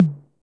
Index of /90_sSampleCDs/Roland - Rhythm Section/DRM_Drum Machine/KIT_TR-808 Kit
DRM 606 TO0P.wav